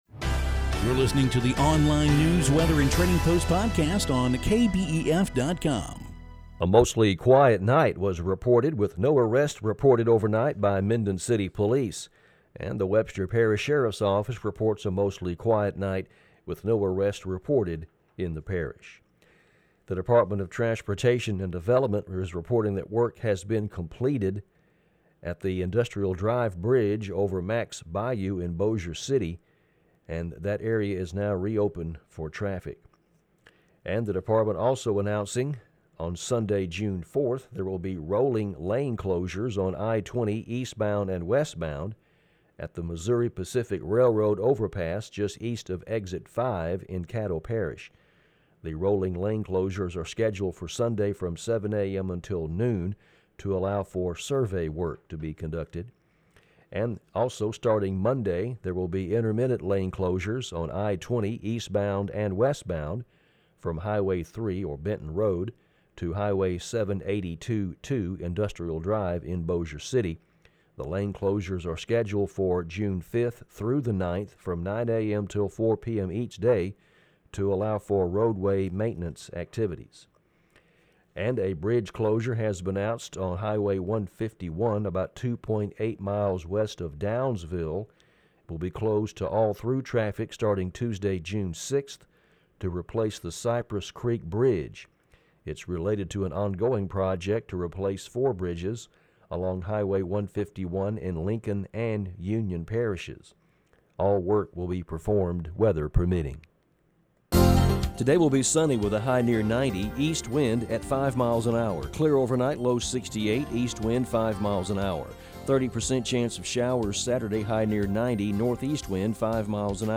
Daily news, weather and trading post podcast.